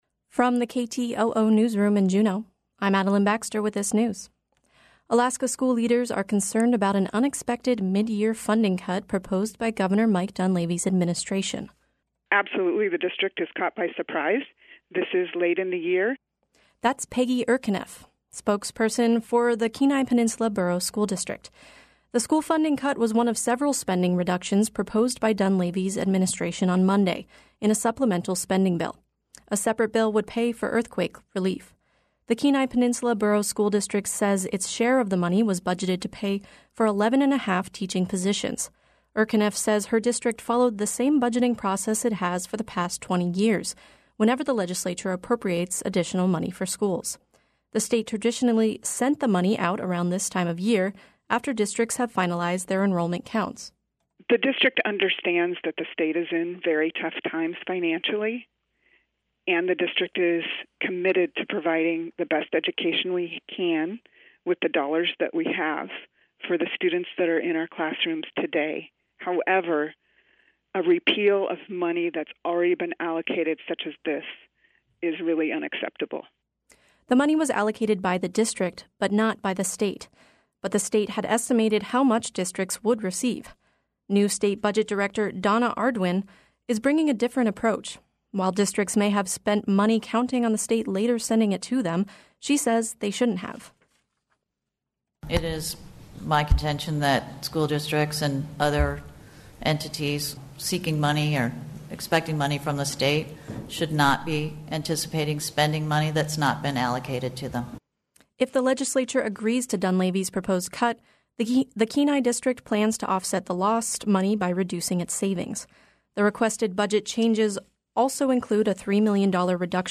Newscast – Tuesday, Jan. 29, 2019